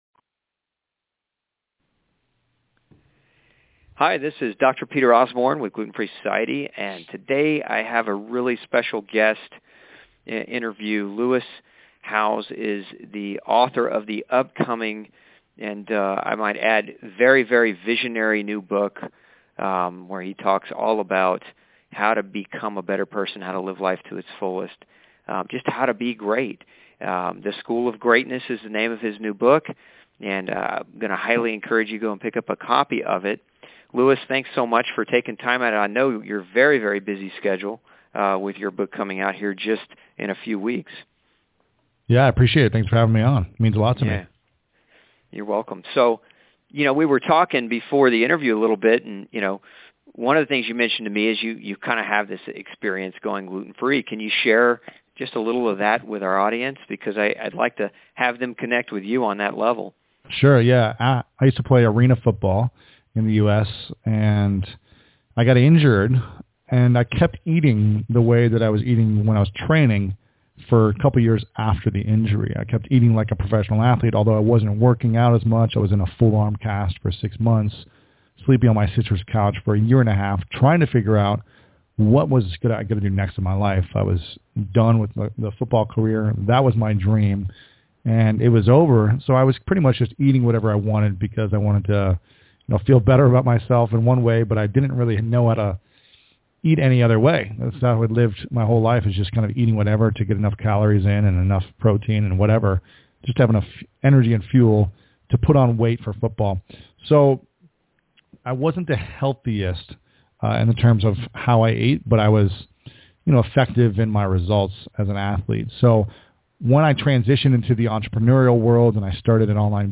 In this interview, Lewis talks about: